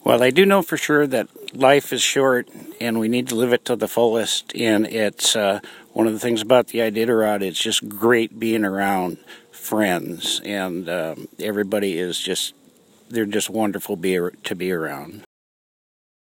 Temperature: 10F / OUTDOORS